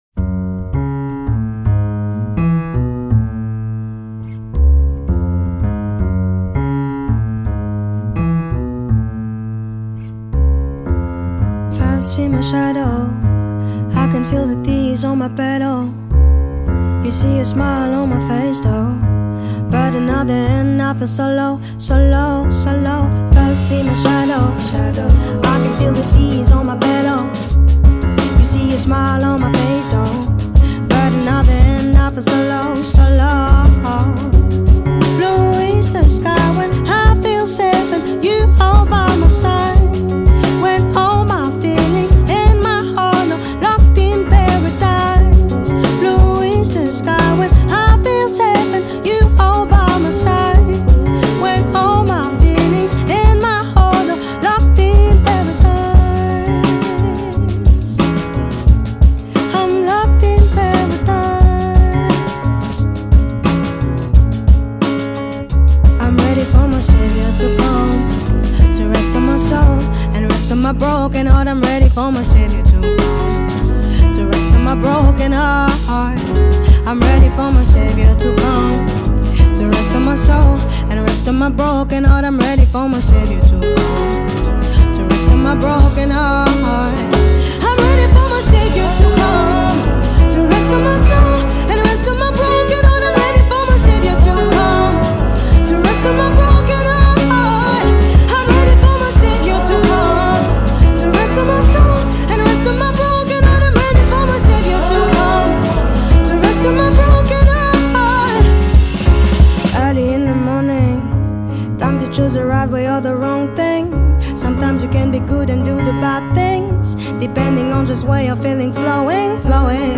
piano
contrebasse
batterie
chant
porté par la voix soul-gospel